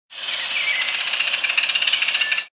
Piaya cayana (squirrel cuckoo)
This is the rattle of a Squirrel Cuckoo (Piaya cayana), from the El Pizote, near Puerto Viejo de Talamanca (Costa Rica),6/23/99.